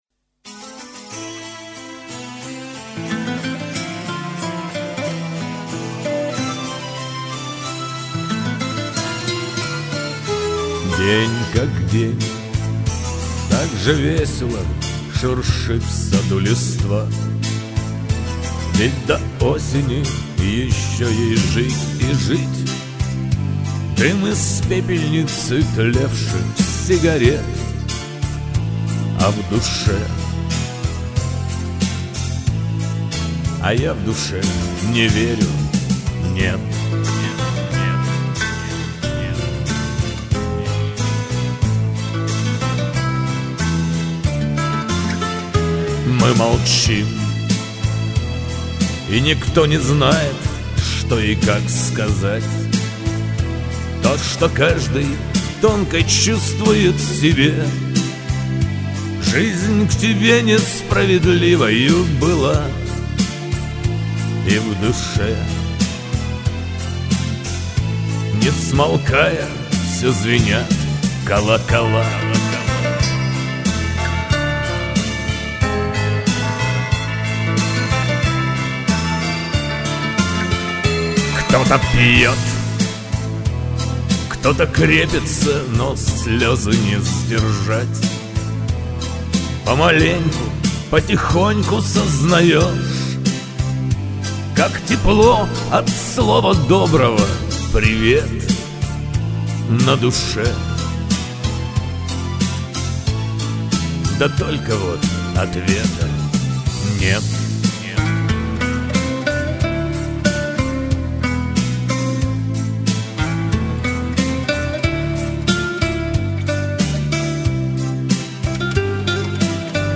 грустные песни выбрали...